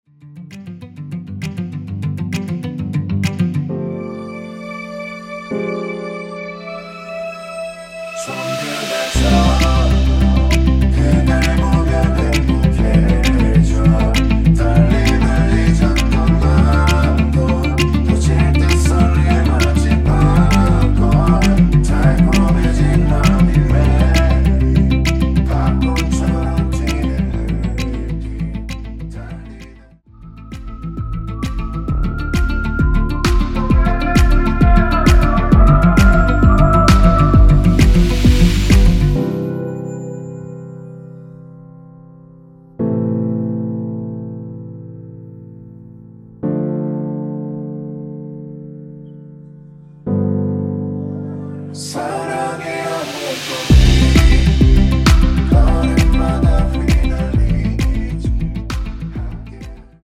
원키에서(-2)내린 코러스 포함된 MR입니다.
앞부분30초, 뒷부분30초씩 편집해서 올려 드리고 있습니다.
중간에 음이 끈어지고 다시 나오는 이유는